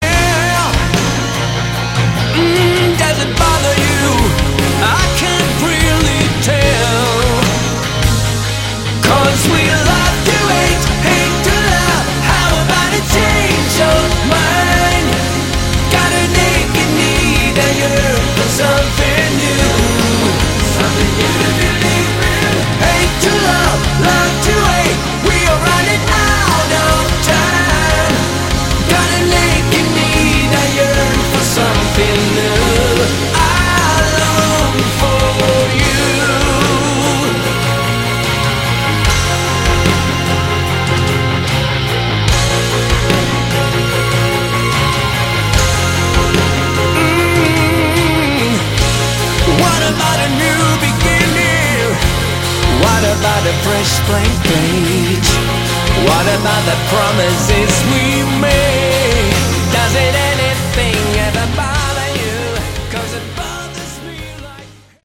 vocals
guitar, bass
keyboards
drums, keyboards
Every song is very catchy and enjoyable. 95